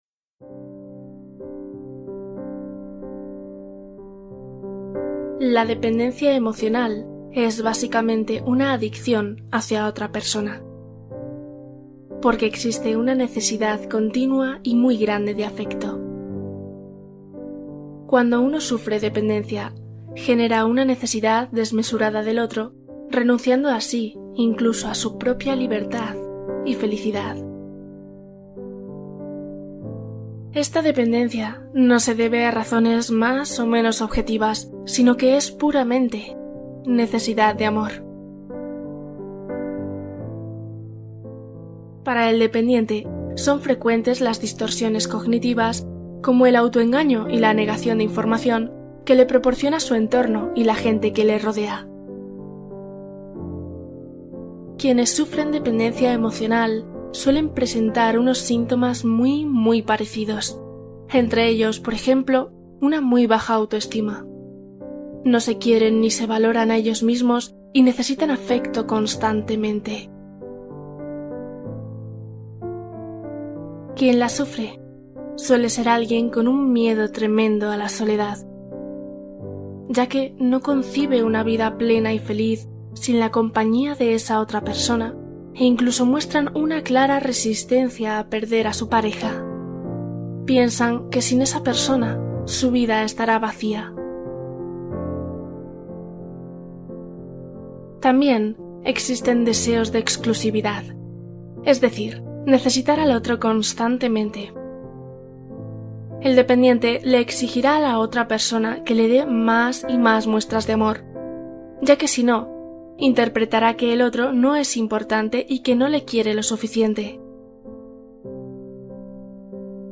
Dejar ir la dependencia emocional: meditación de liberación